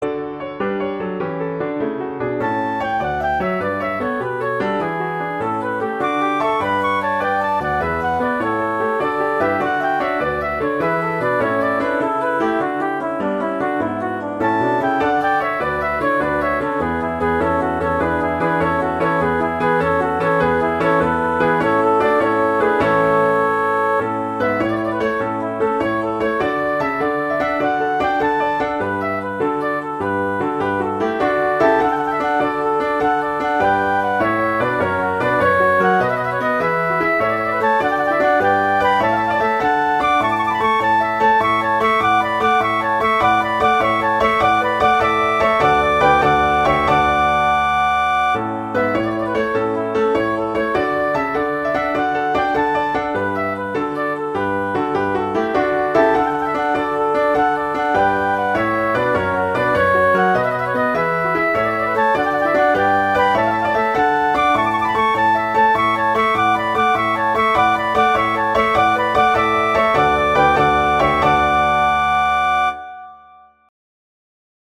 classical, wedding, traditional, easter, festival, love
D major